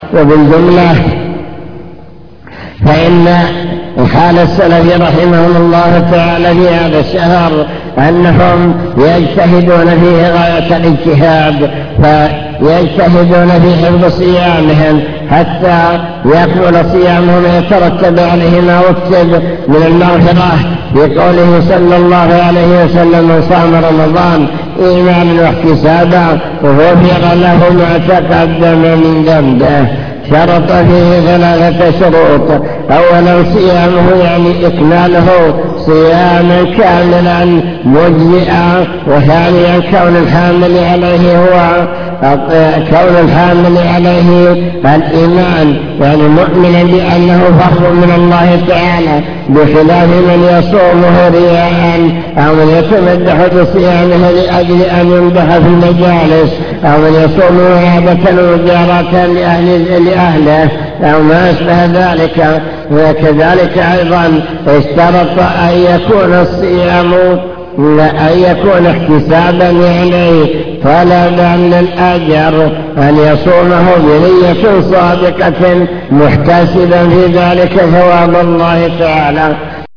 المكتبة الصوتية  تسجيلات - محاضرات ودروس  مجموعة محاضرات ودروس عن رمضان هدي السلف الصالح في رمضان